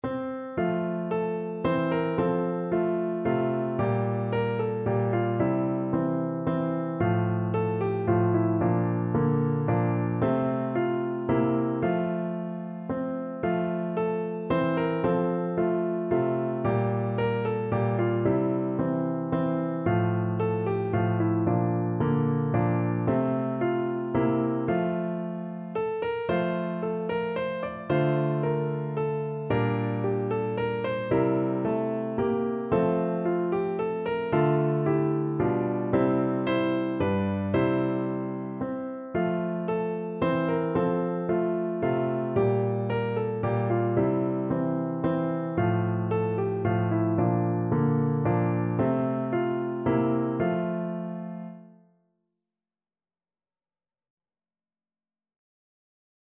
Traditional Trad. The Ash Grove (Llywn Onn) (Traditional Welsh) Piano version
No parts available for this pieces as it is for solo piano.
F major (Sounding Pitch) (View more F major Music for Piano )
Moderately Fast ( = c. 112)
3/4 (View more 3/4 Music)
Traditional (View more Traditional Piano Music)
ashgrove_PNO.mp3